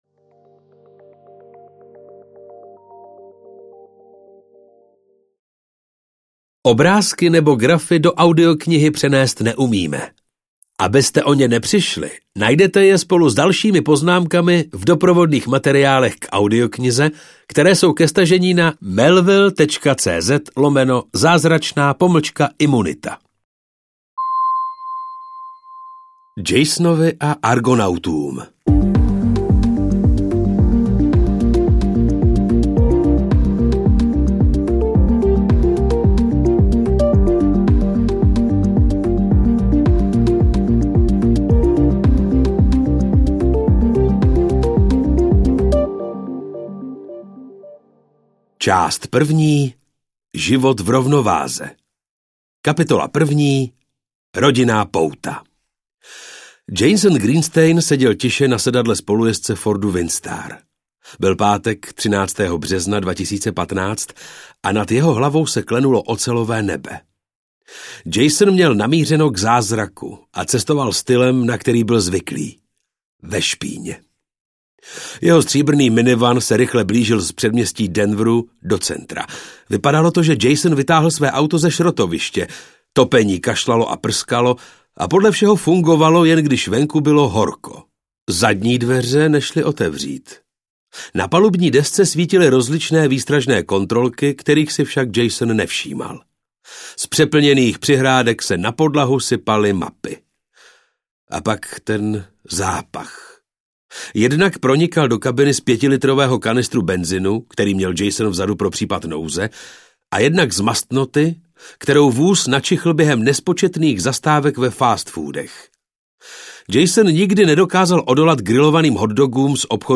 Zázračná imunita audiokniha
Ukázka z knihy